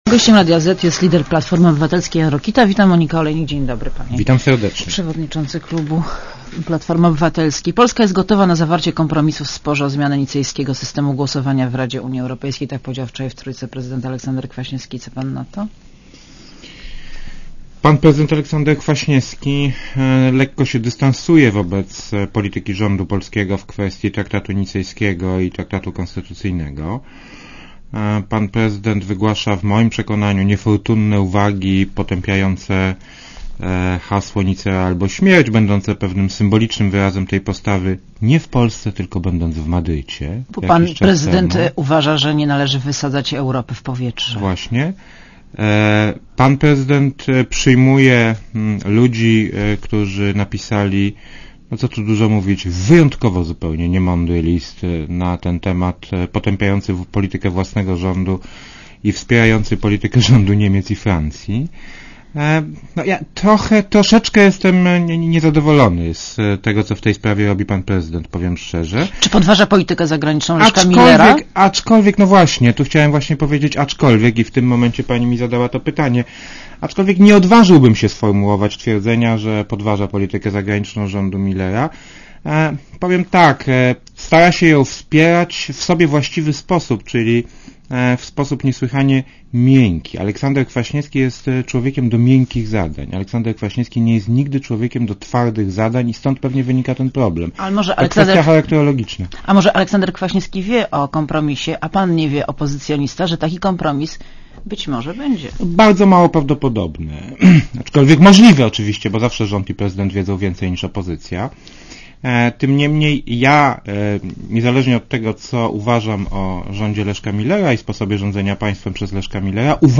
Jan Rokita w Radiu Zet (PAP)
Posłuchaj wywiadu (2.6 MB) : Gościem Radia Zet jest lider Platformy Obywatelskiej – Jan Rokita.